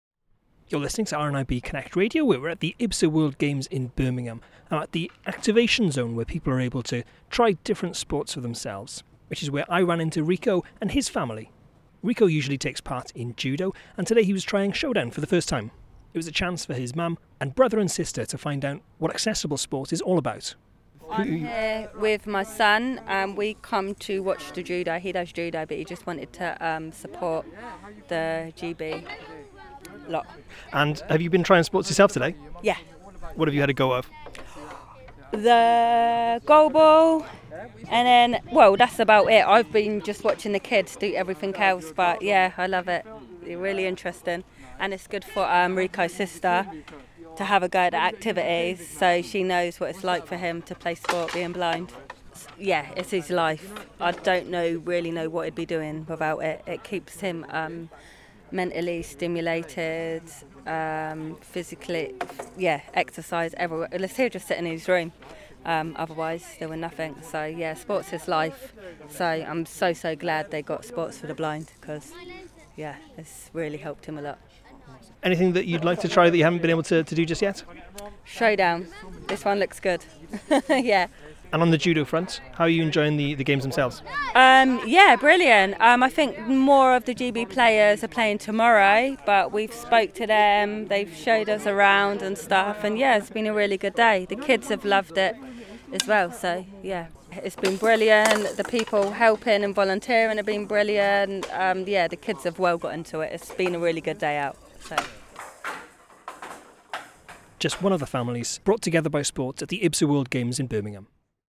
It’s not just about spectating at the IBSA World Games in Birmingham, there are plenty of chances to get hands on and try some sports for yourself.